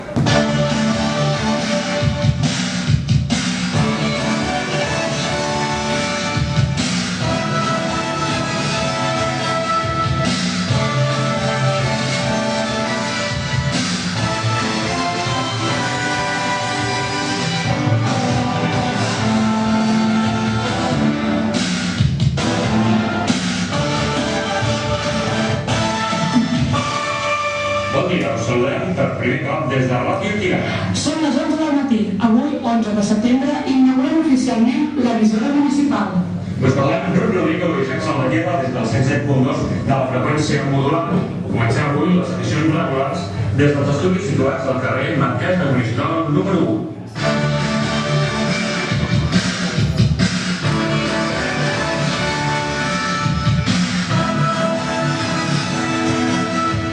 Emissió inaugural, identificació de l'emissora, data, benvinguda i adreça dels estudis.
Qualitat de l'àudio defectuosa.